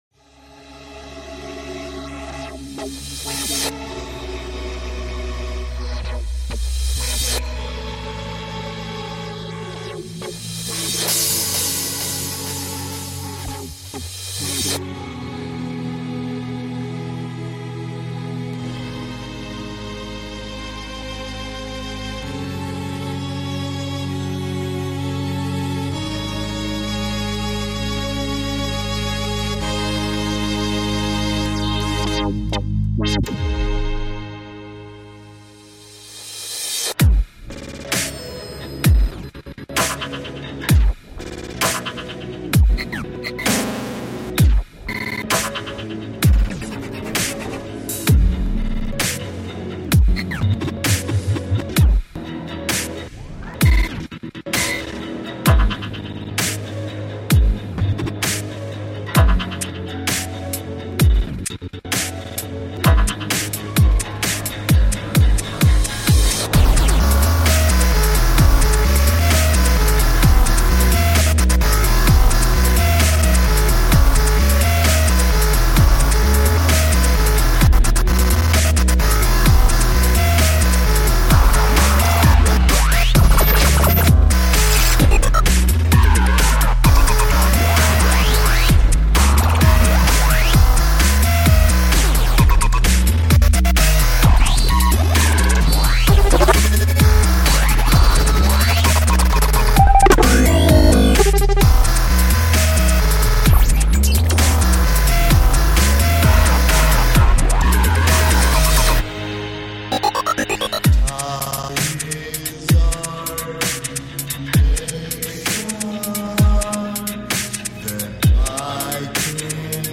complete with lyrics.